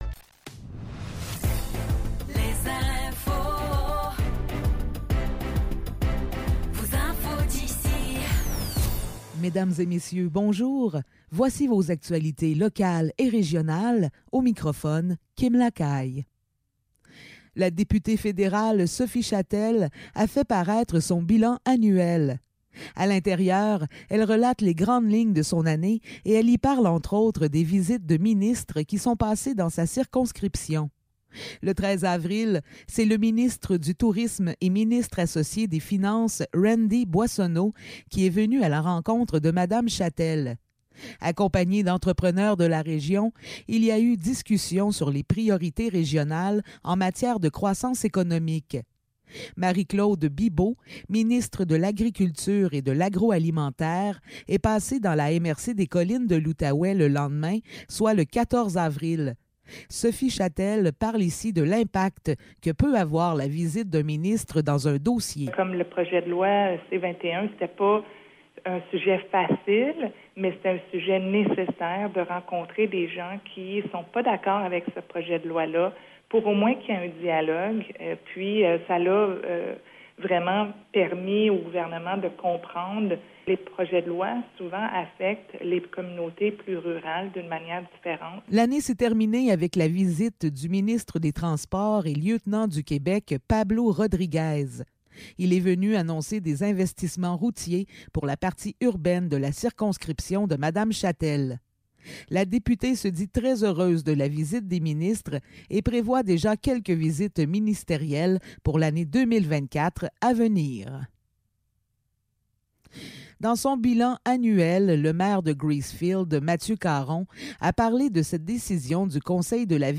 Nouvelles locales - 29 décembre 2023 - 16 h